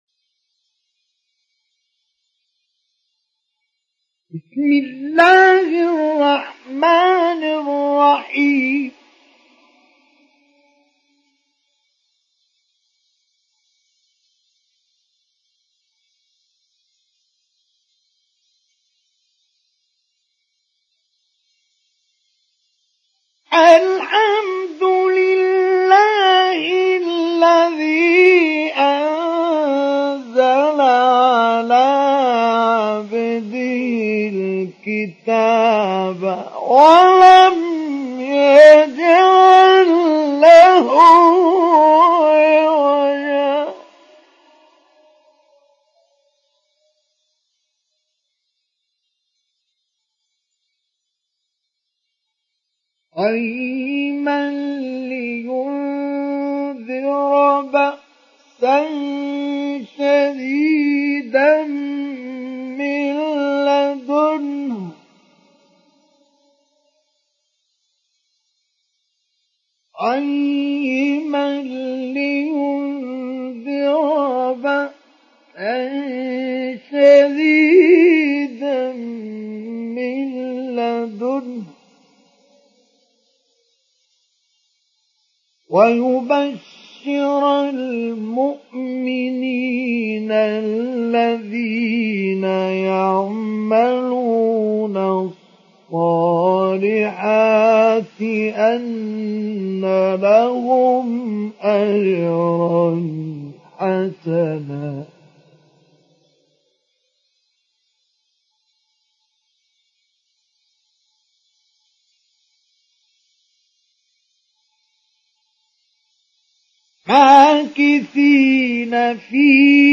Surat Al Kahf Download mp3 Mustafa Ismail Mujawwad Riwayat Hafs dari Asim, Download Quran dan mendengarkan mp3 tautan langsung penuh
Download Surat Al Kahf Mustafa Ismail Mujawwad